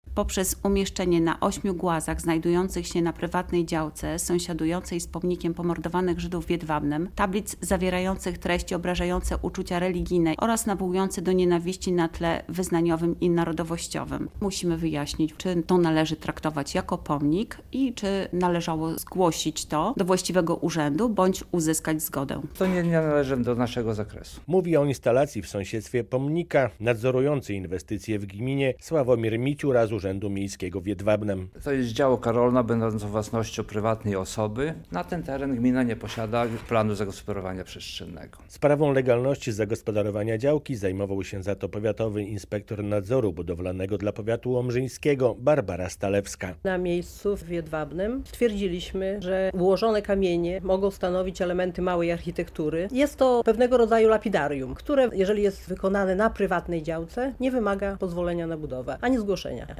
Prokuratura zajmuje się sprawą głazów z kontrowersyjnymi tablicami przy pomniku w Jedwabnem - relacja